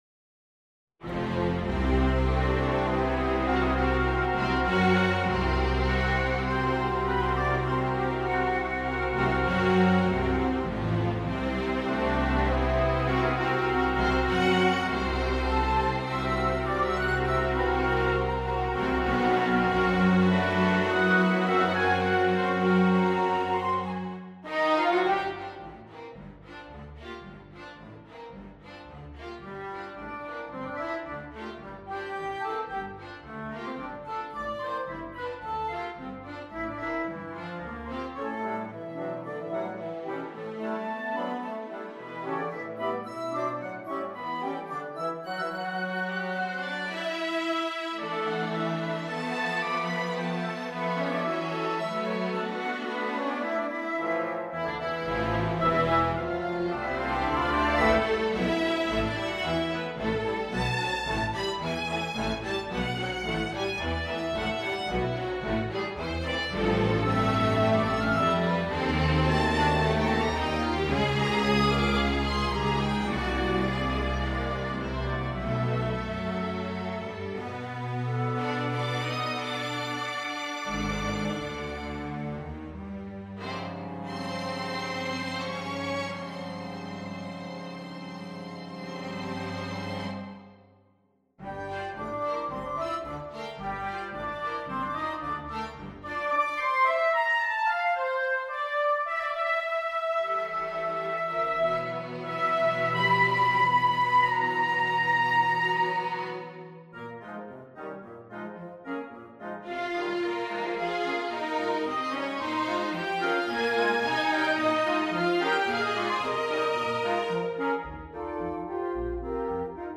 for orchestra
Strings (Violin 1, Violin 2, Viola, Cello, Bass)